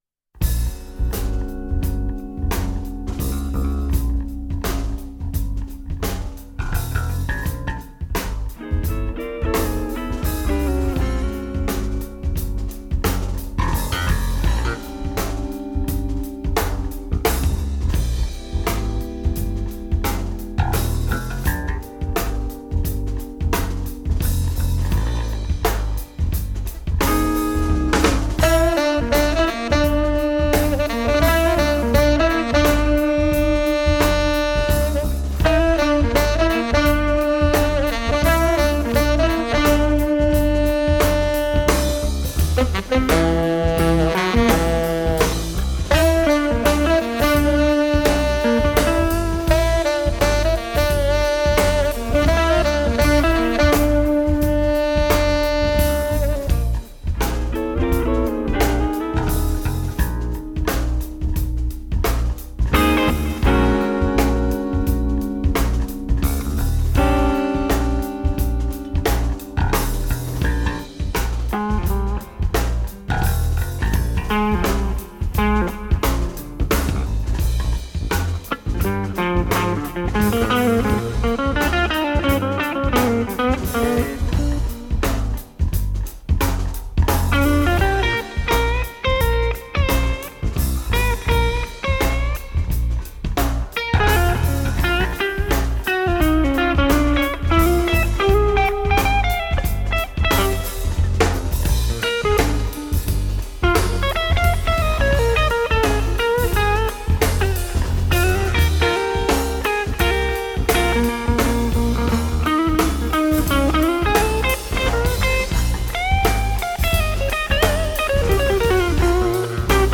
guitar
drums
saxophon
bass Écouter un extrait